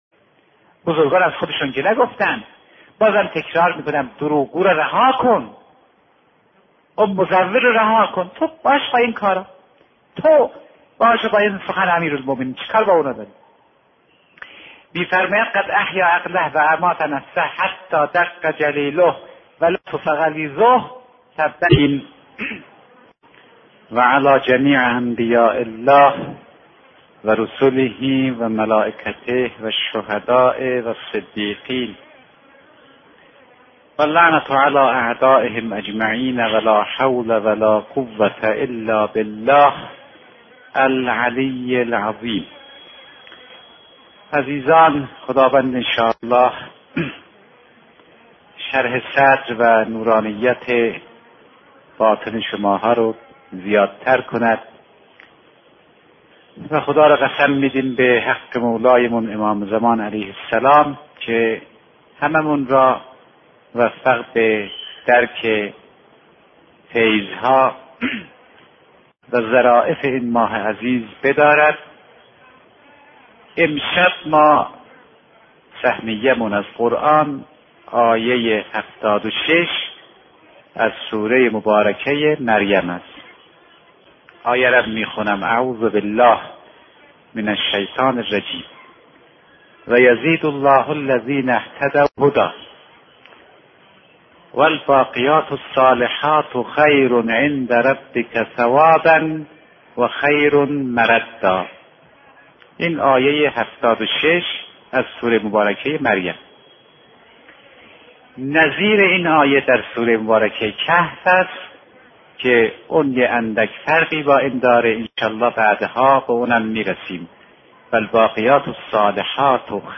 دانلود بیانات عارف بزرگوار حضرت آیت الله فاطمی نیا پیرامون مباحث اخلاق در قرآن با عنوان «هدایت عامه»
این خطابه بر مبنای آیه ۷۶ سوره مریم درباره هدایت و مراتب آن بحث می‌کند.